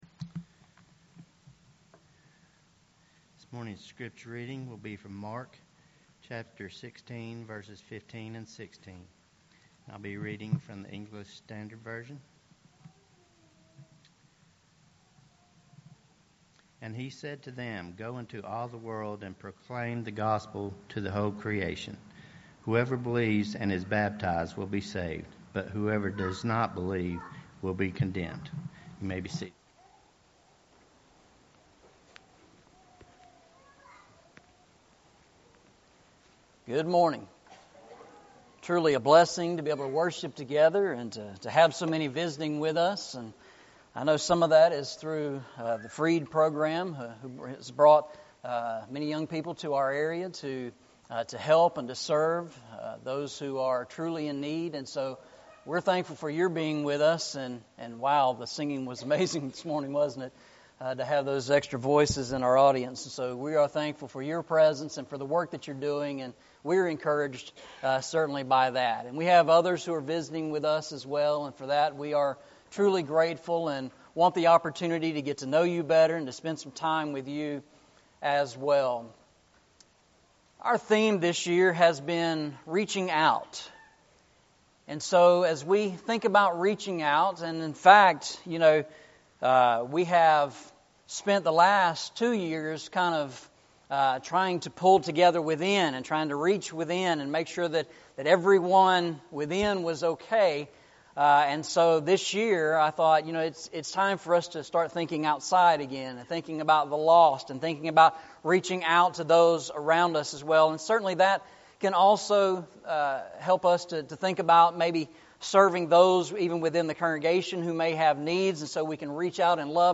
Eastside Sermons Passage: Mark 16:15-16 Service Type: Sunday Morning « Questions and Answers Walking Through the Bible